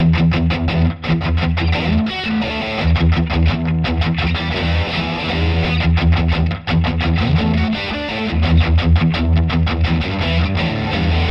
烧烤大师 85bpm E
描述：畸变的例子在E
Tag: 85 bpm Rock Loops Guitar Electric Loops 1.90 MB wav Key : E